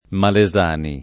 [ male @# ni ]